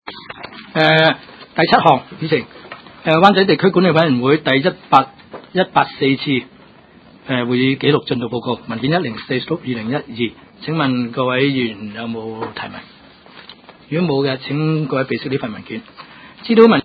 区议会大会的录音记录
湾仔区议会第七次会议